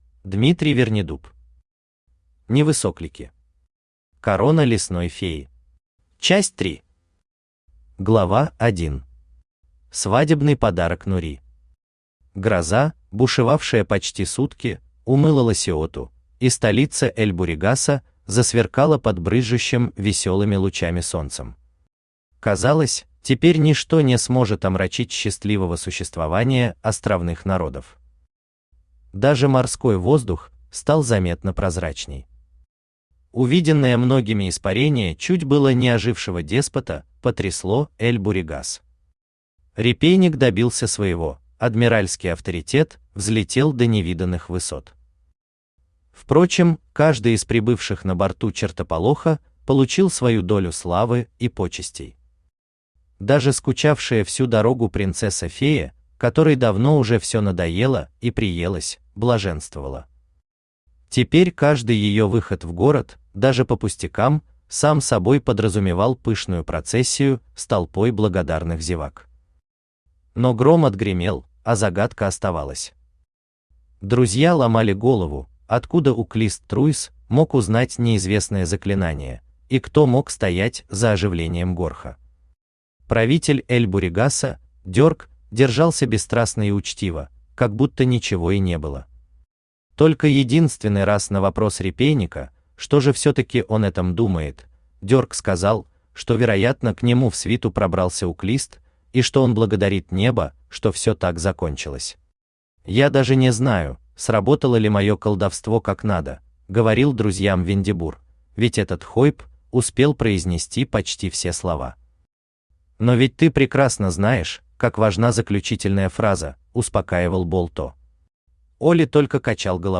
Часть 3 Автор Дмитрий Вернидуб Читает аудиокнигу Авточтец ЛитРес.